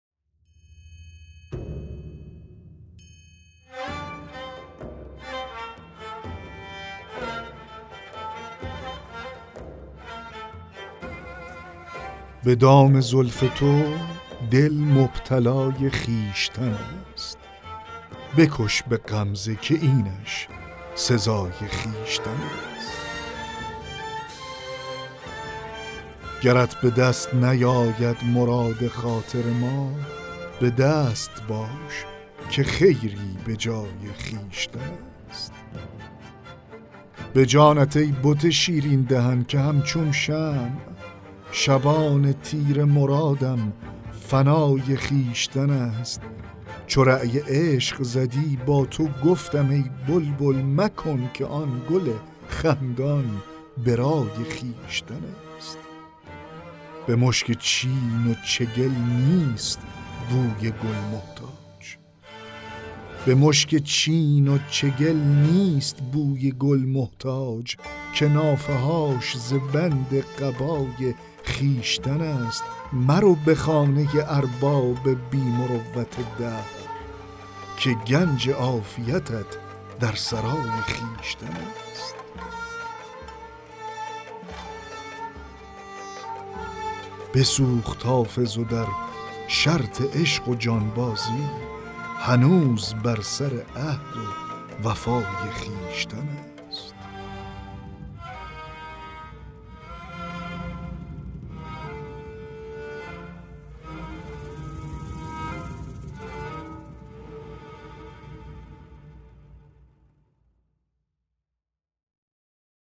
دکلمه غزل 50 حافظ
دکلمه-غزل-50-به-دام-زلف-تو-دل-مبتلای-خویشتن-است-.mp3